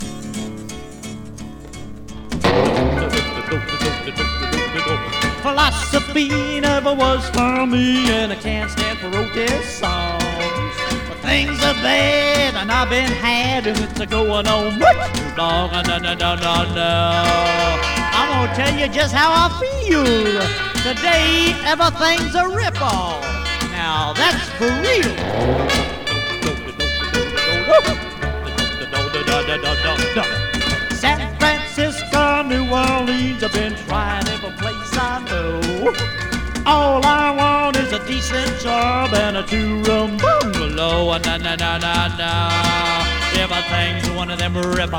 低予算、多重録音による音の質感も最高な大傑作。
Rock, Rockabilly　USA　12inchレコード　33rpm　Mono